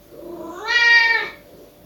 meow4.wav.wav.mp3